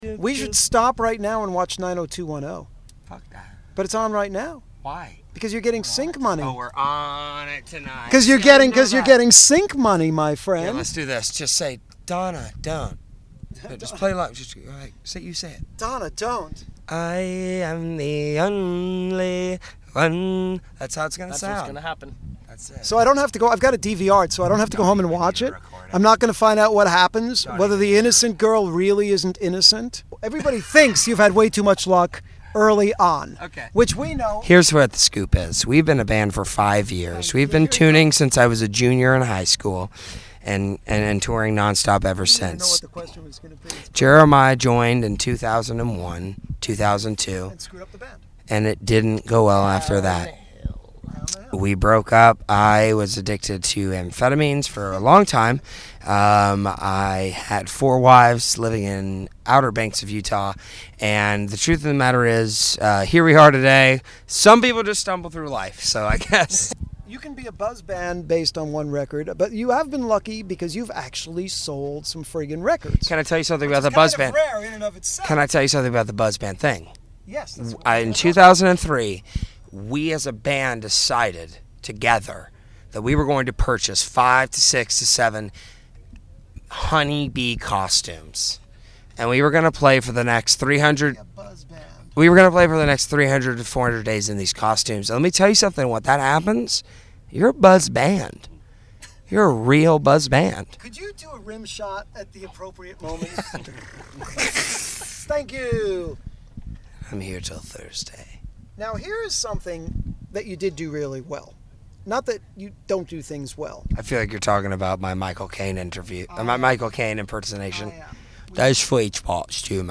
Also, be sure to listen for our exclusive “Musical Sandwich” featuring two of the interviewed artist’s songs as the bread and as many musical fillings as they can muster up in-between!
This Week's Interview (06/21/2009):